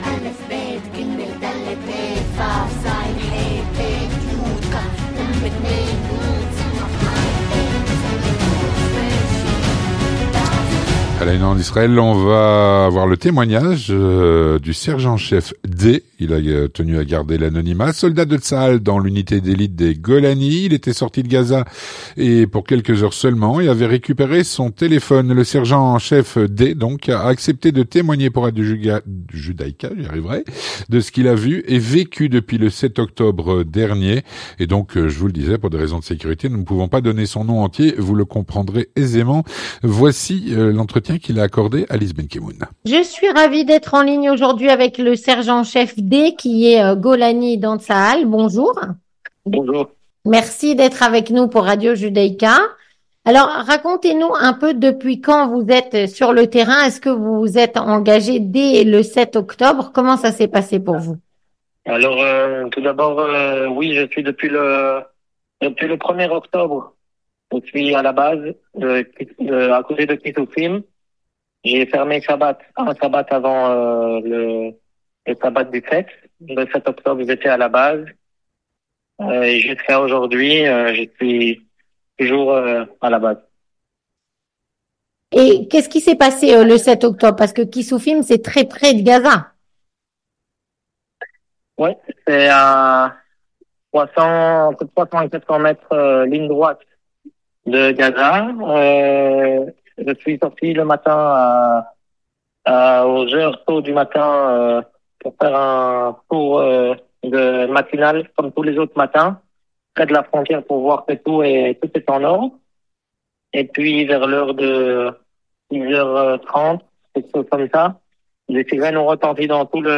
Il a donc récupéré son téléphone, puisque c’est interdit sur le terrain. Il a accepté de témoigner pour Radio Judaïca de ce qu’il a vu et vécu depuis le 7 octobre.